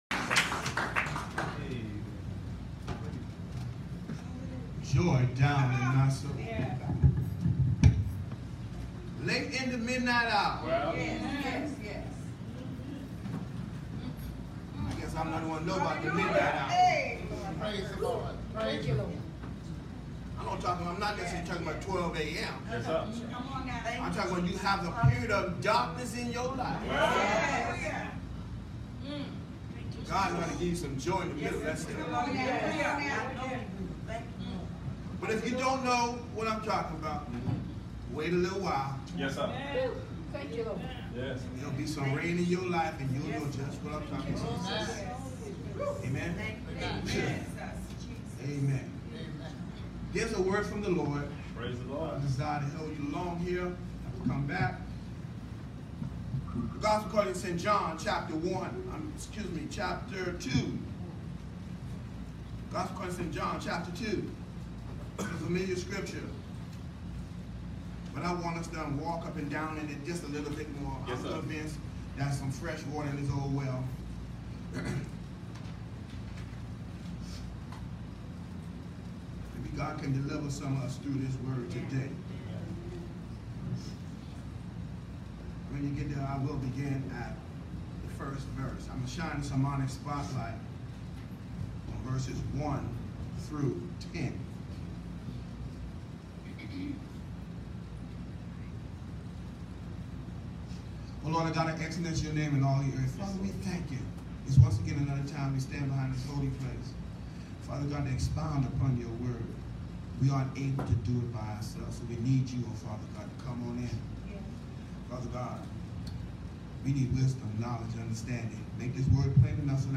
New Jerusalem MB Church July 22, 2018 11:00 am JOHN 2: 1-11 The Message: “Bring Back The Wine”